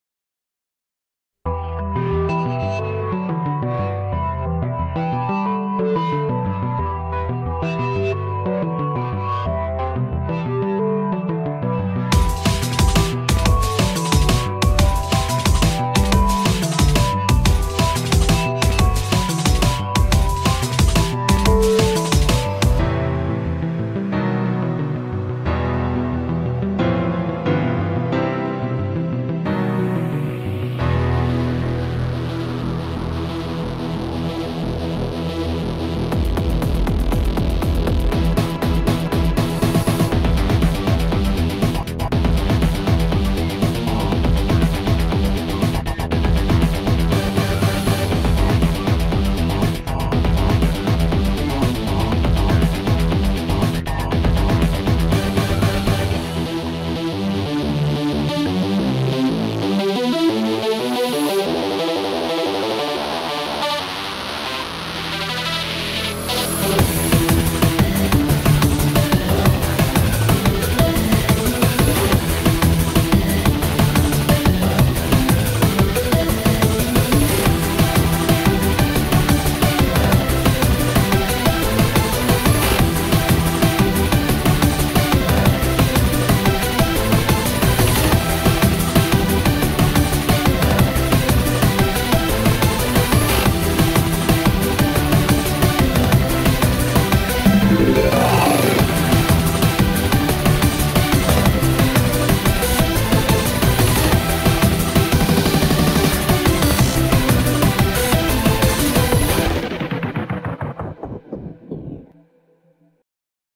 BPM90-180